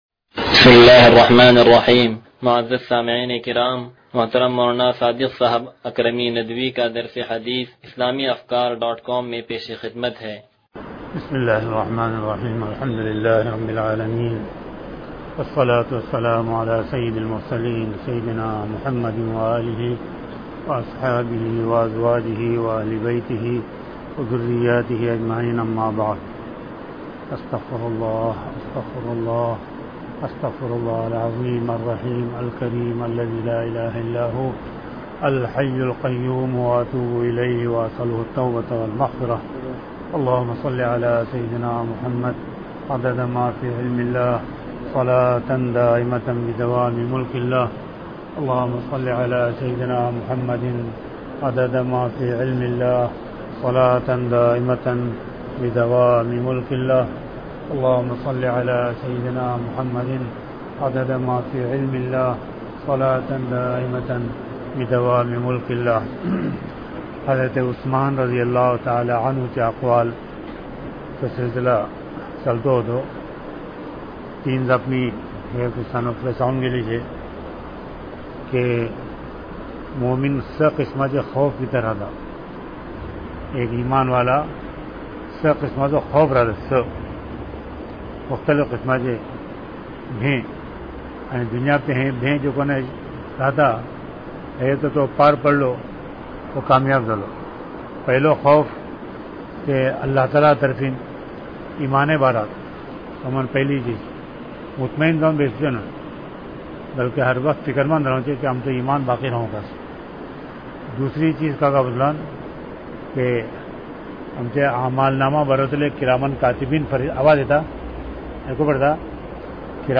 درس حدیث نمبر 0151